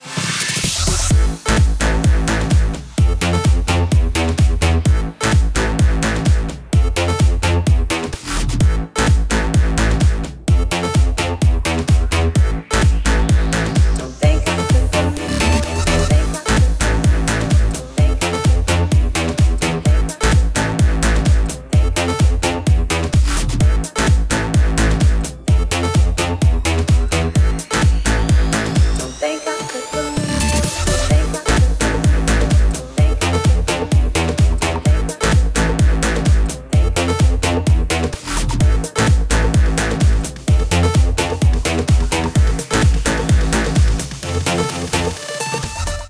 • Качество: 128, Stereo
громкие
remix
dance
club
Bass
electro house
Заводной клубнячок.